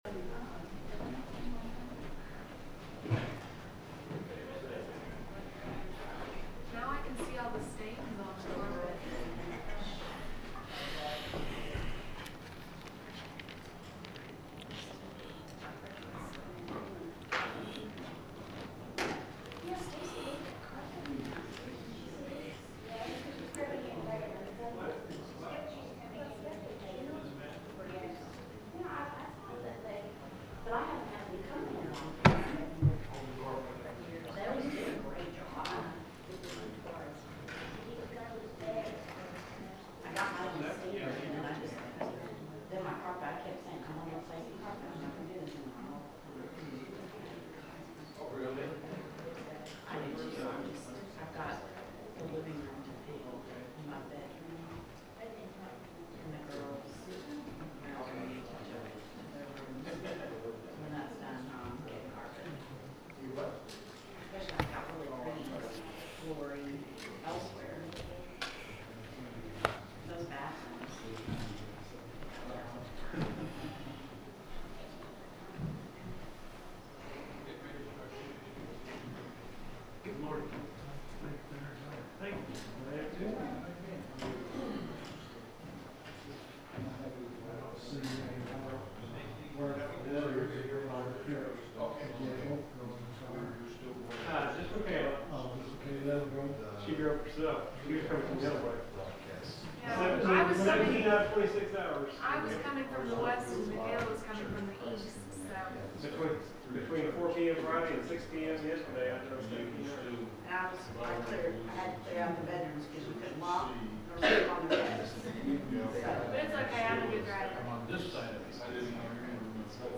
The sermon is from our live stream on 11/23/2025